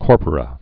(kôrpər-ə)